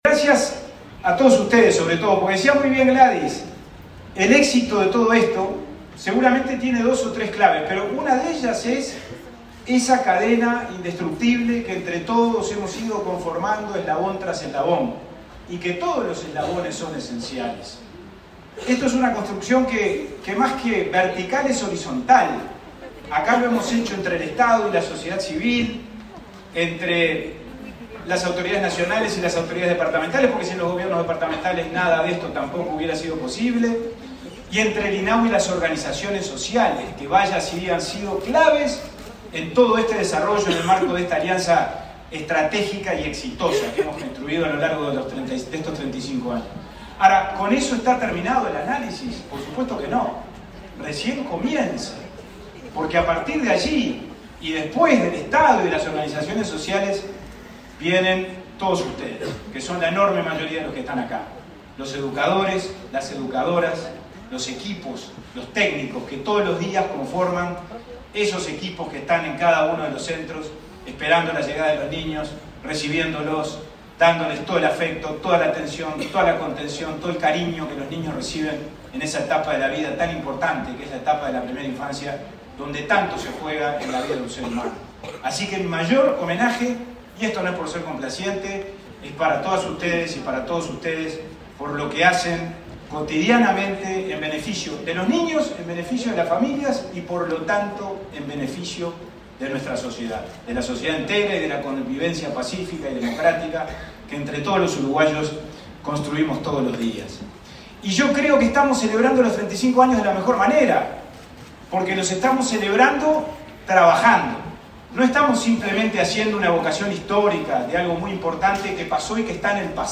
Palabras de autoridades en seminario de primera infancia
Palabras de autoridades en seminario de primera infancia 01/09/2023 Compartir Facebook X Copiar enlace WhatsApp LinkedIn El presidente del Instituto del Niño y el Adolescente del Uruguay (INAU), Pablo Abdala, y la directora nacional de Desarrollo Social, Cecilia Sena, participaron, en Soriano, en el 9.° Seminario Regional de Primera Infancia.